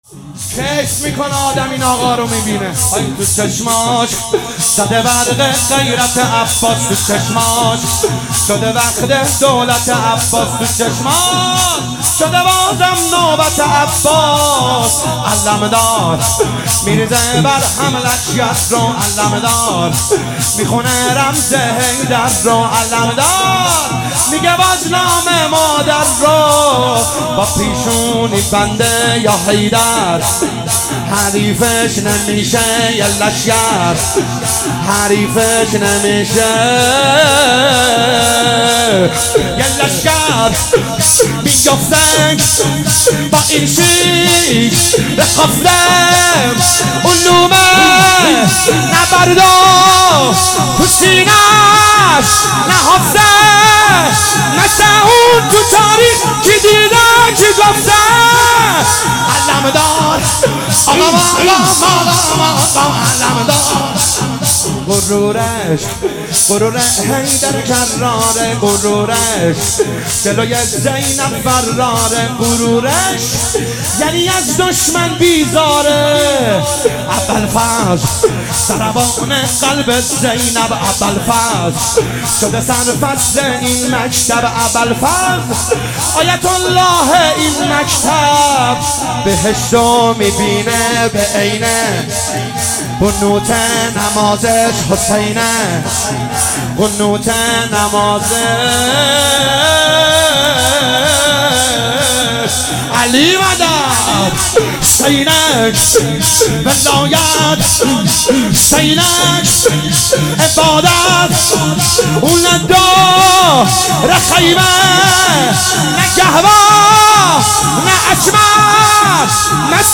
هیئت جنت العباس(ع) کاشان